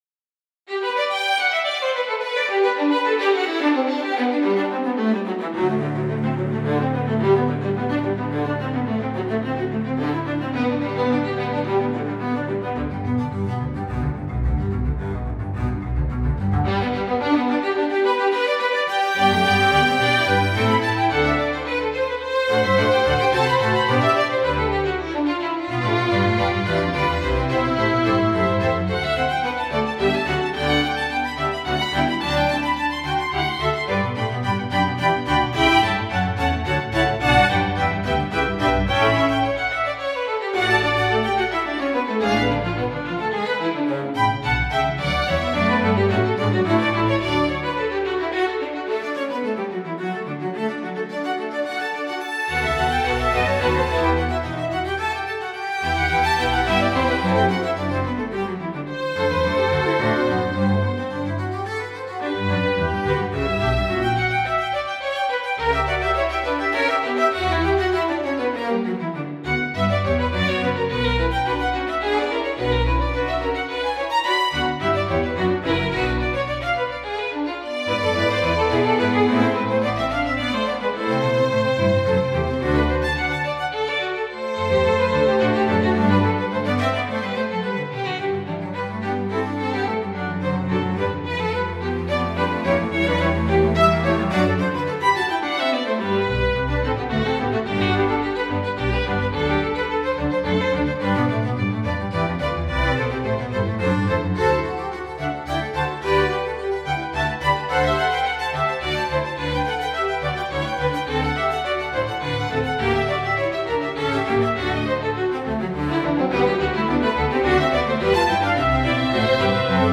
all created with samples
Most of the pieces are played between 2002 and 2014 and also mixed as it was normal at that time...